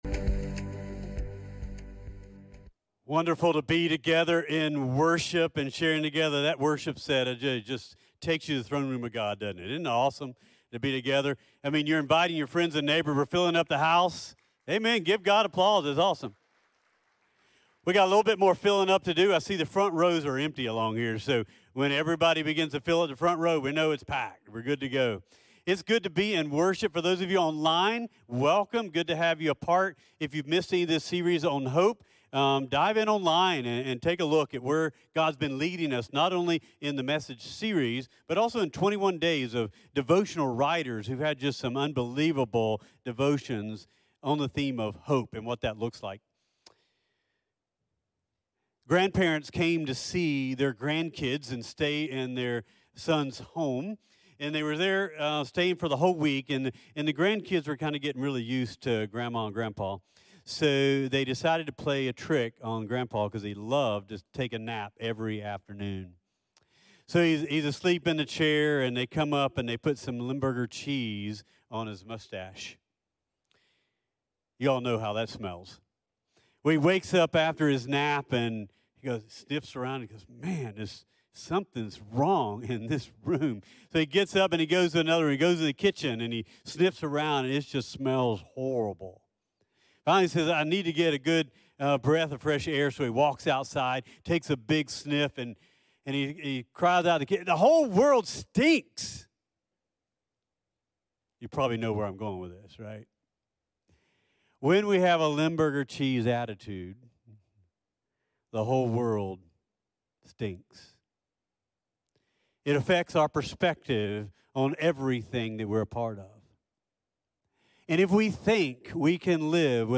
CTK-Clipped-Sermon.mp3